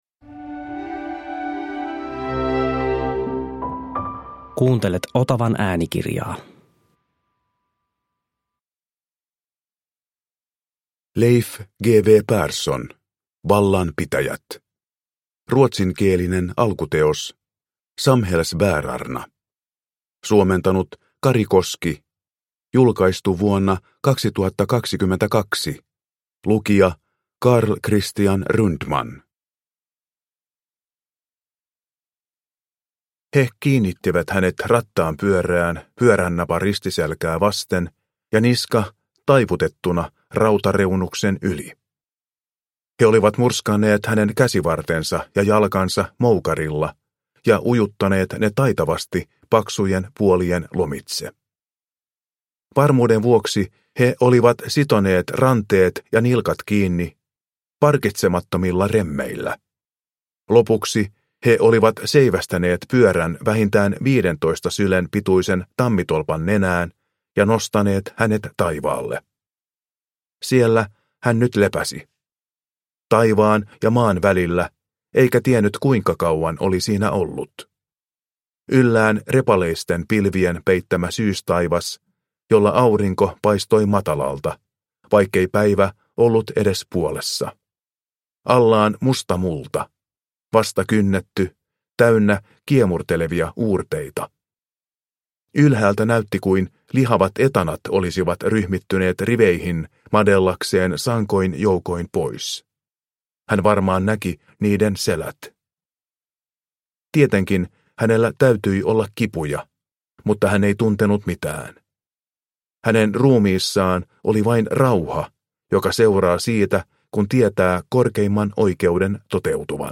Vallanpitäjät – Ljudbok – Laddas ner